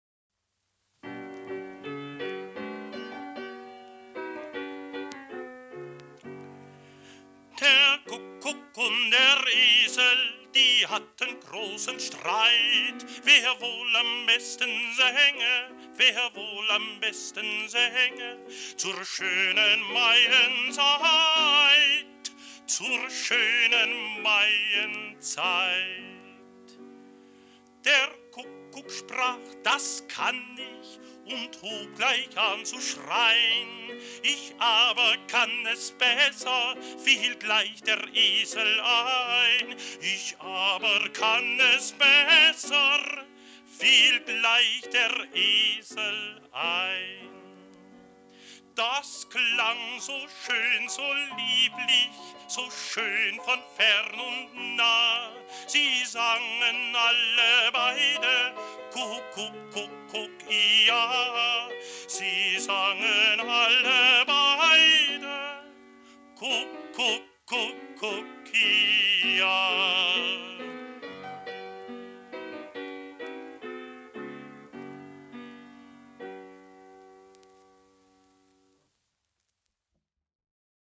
Kinderlied